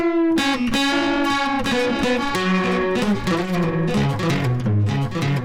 crazy_guitar.wav